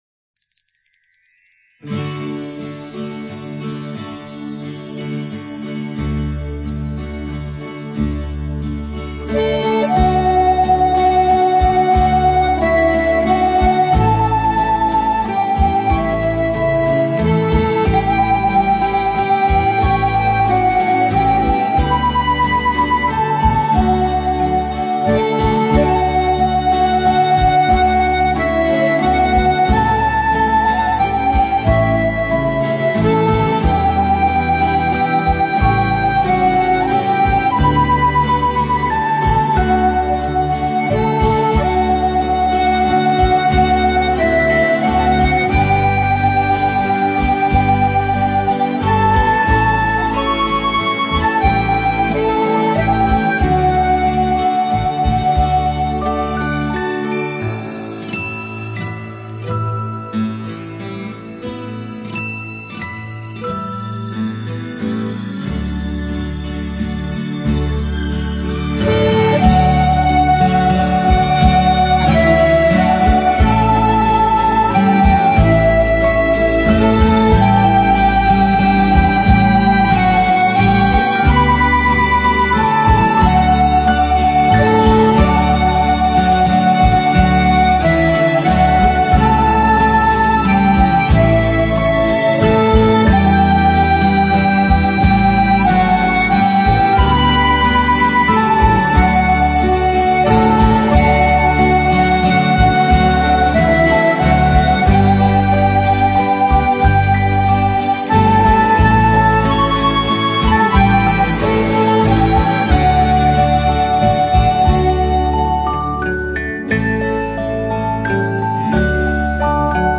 Жанр: Instrumental [Губная гармошка]